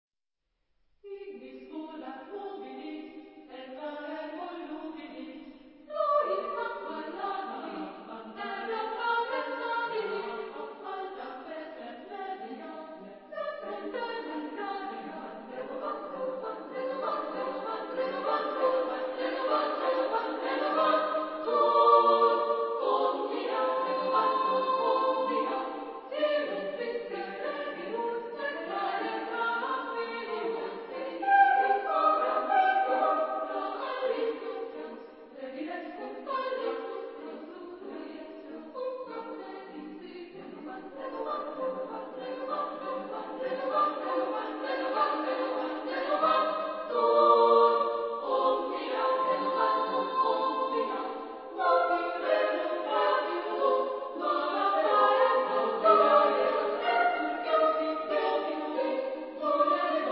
Epoque: 20th century
Genre-Style-Form: Vocal piece ; Secular
Type of Choir: SSAA OR TTBB  (4 equal voices )
Tonality: free tonality